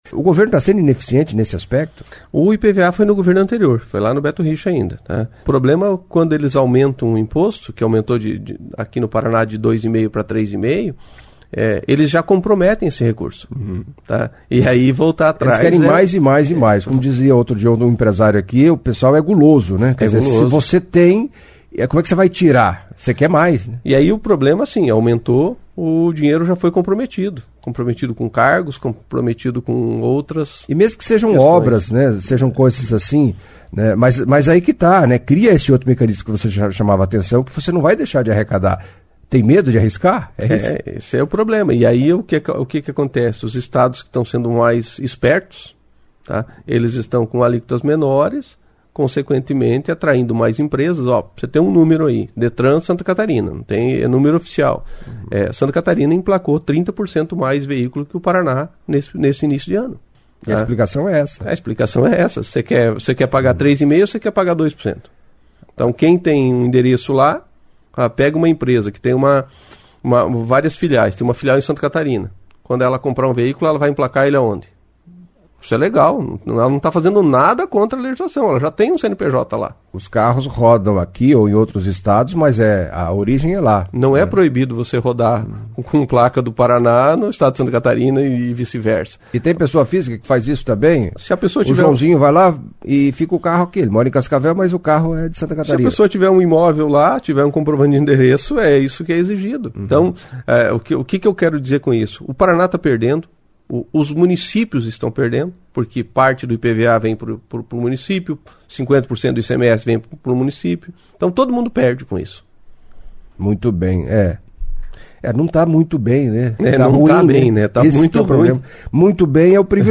Em entrevista à CBN Cascavel nesta quinta-feira (08)